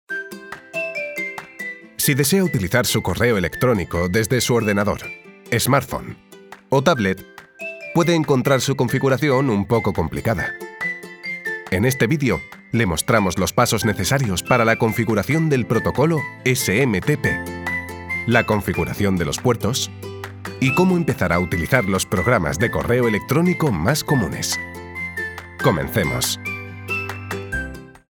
Explainer Videos
Baritone